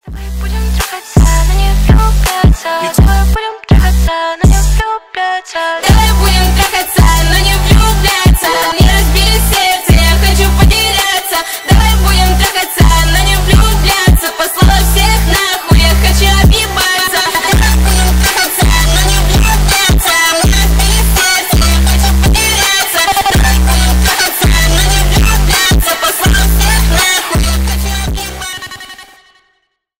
Рок Металл
громкие # злые